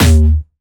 Jumpstyle Kick 3
11 D#2.wav